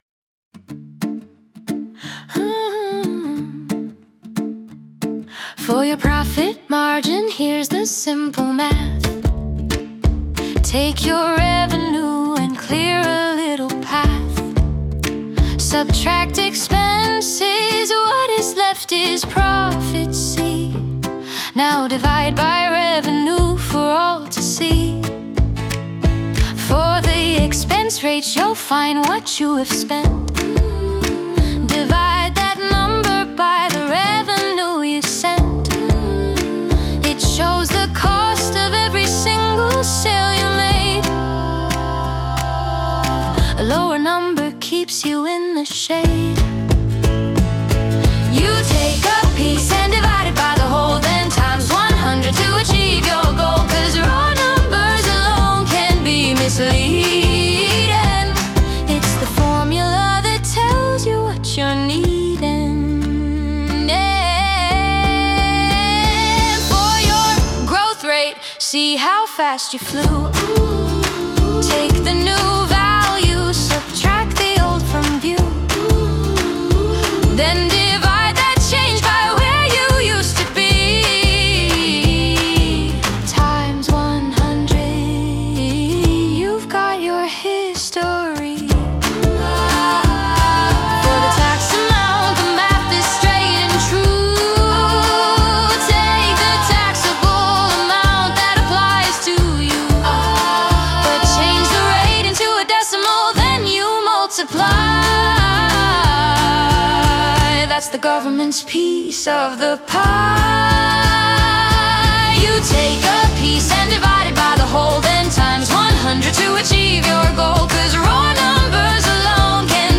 The Sing Along Experience